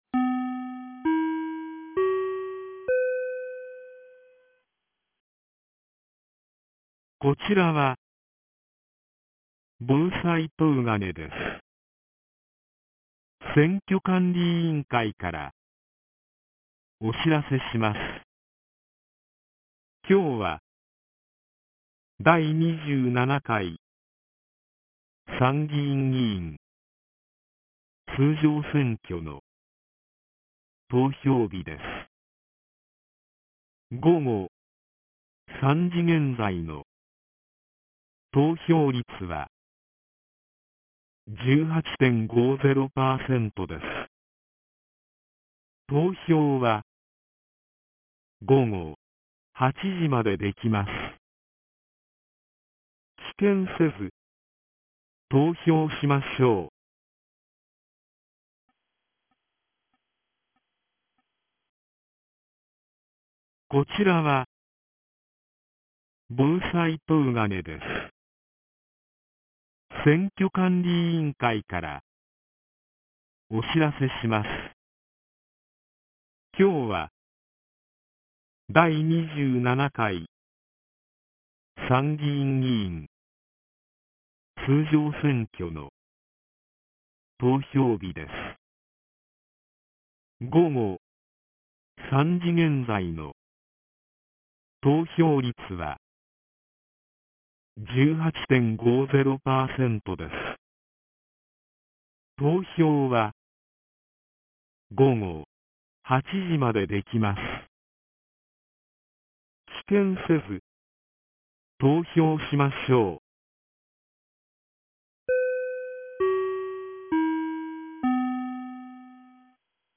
2025年07月20日 15時13分に、東金市より防災行政無線の放送を行いました。